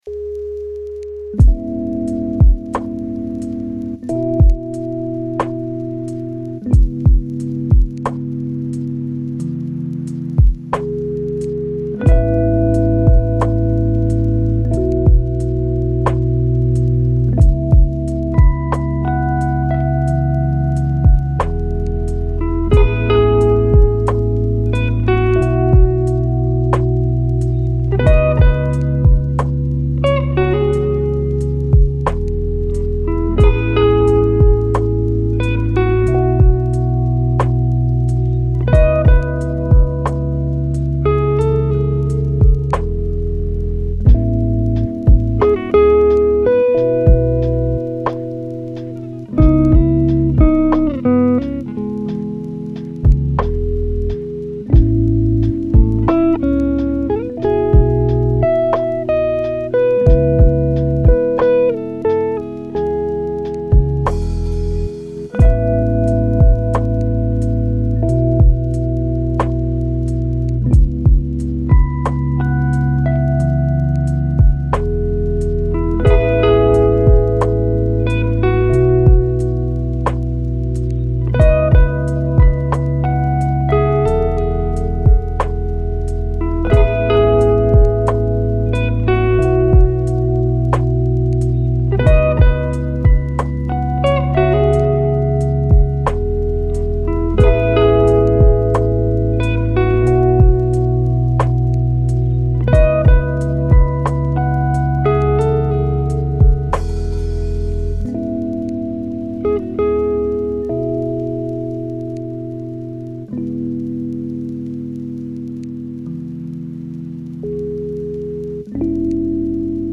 Flux Créatif : Ambiance Naturelle